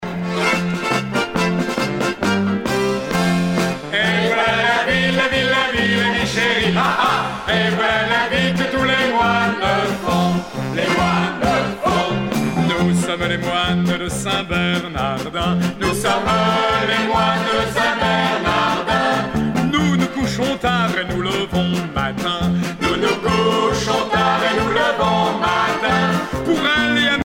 danse : marche
Genre strophique
Pièce musicale éditée